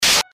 voicenoise.mp3